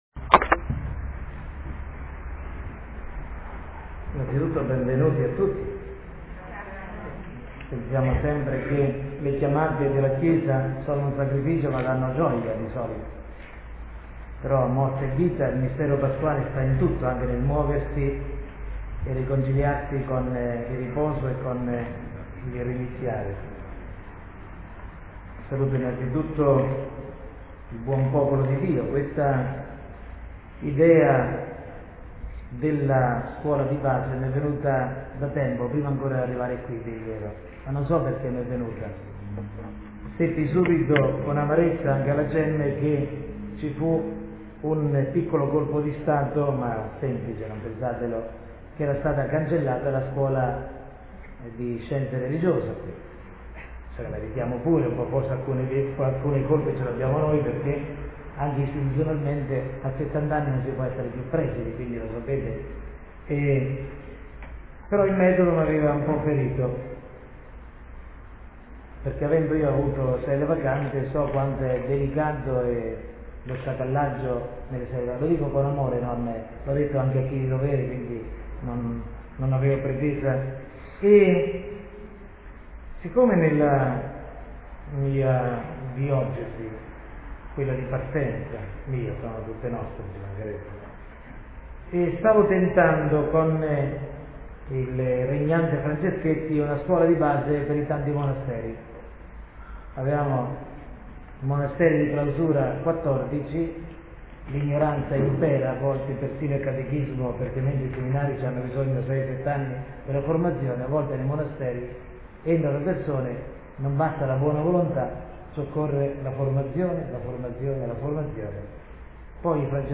Prolusione formazione teologica
prolusione-formazione-teologica-2008.mp3